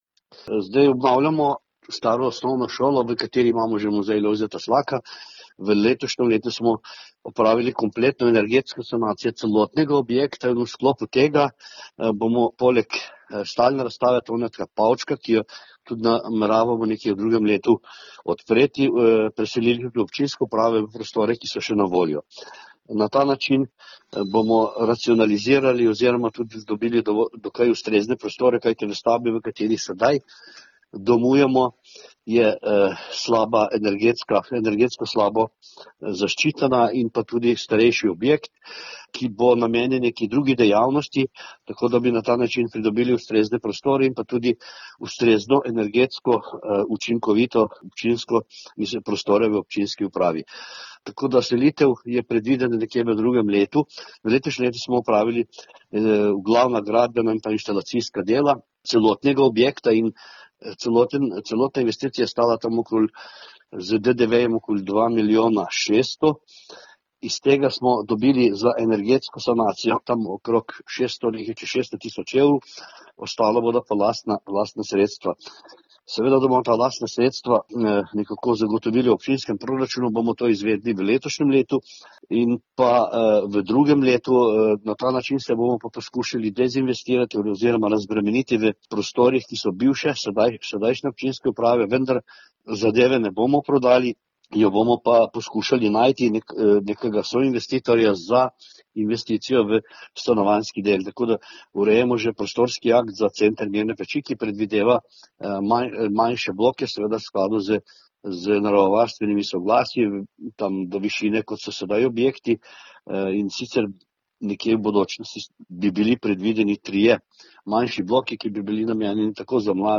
Mirnopeški župan Andrej Kastelic o selitvi občinske uprave in stanovanjih